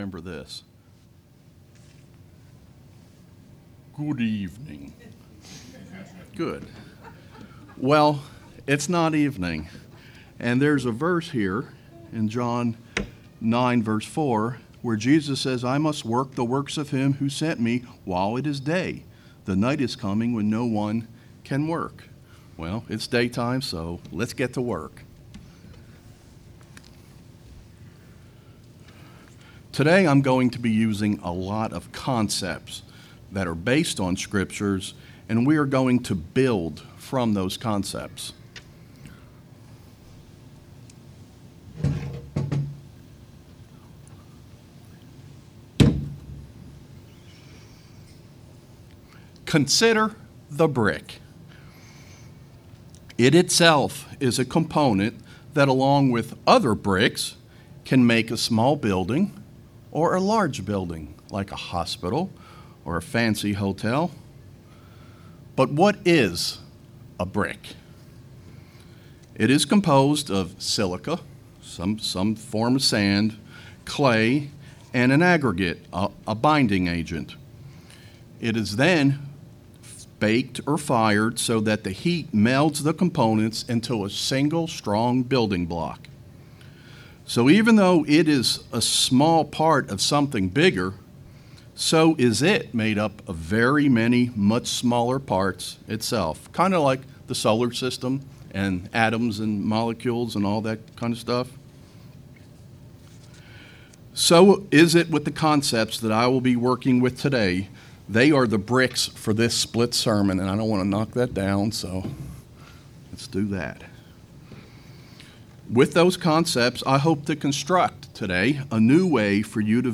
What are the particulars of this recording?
Given in Knoxville, TN